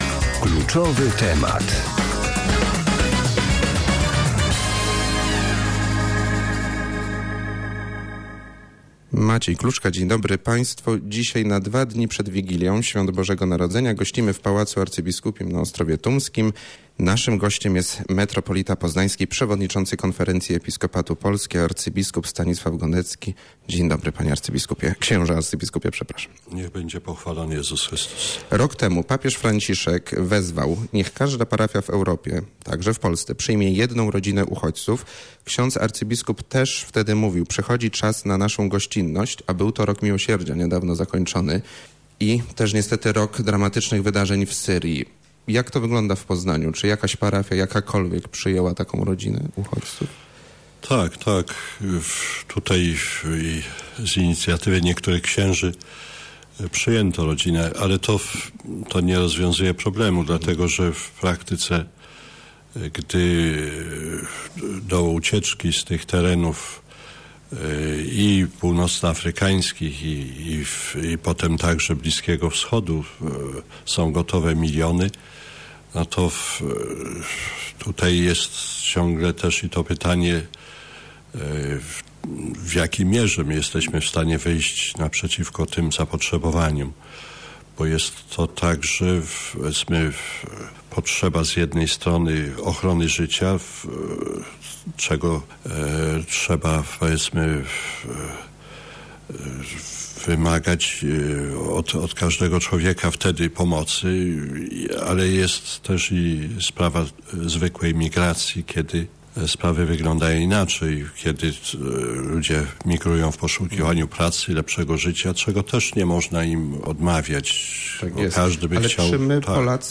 00ubac40rmzlk0s_rozmowa_arcybiskup.mp3